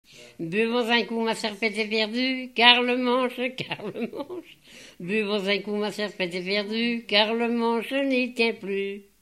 circonstance : bachique
Genre brève
Pièce musicale inédite